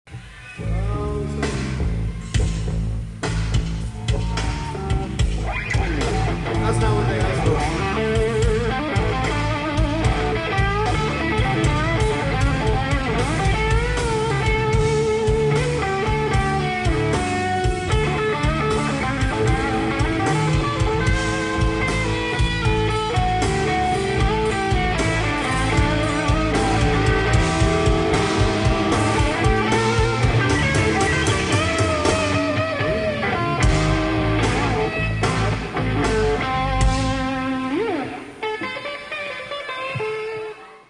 07/14/93 - Brixton Academy: London, England [105m]
A little bluesy jam leads to 'Sonic Reducer'
[mp3 soundbyte of blues jam] (654k)